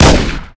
pistol.ogg